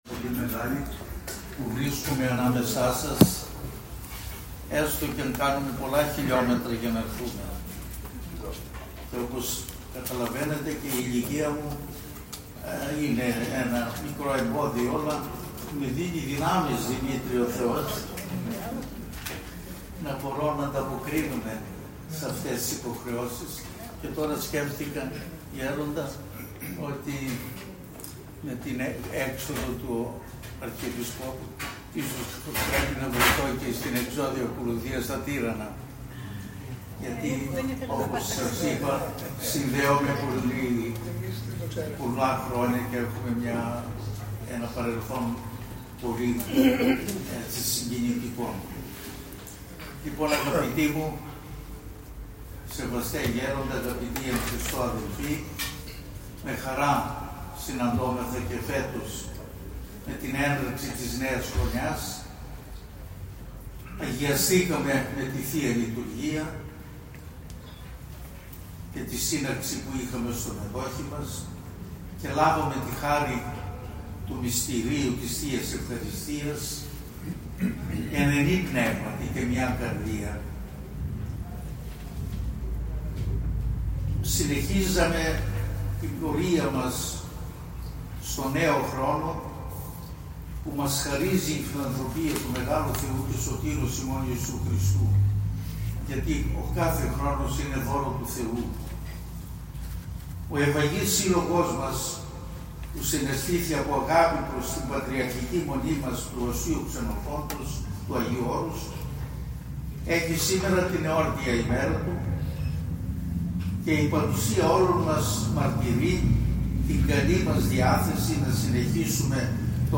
ΓΕΝΙΚΗ ΣΥΝΕΛΕΥΣΗ 2025 - Σύλλογος Φίλων Ἱ.Μ. Ξενοφῶντος Ἁγίου Ὄρους
Ὁμιλία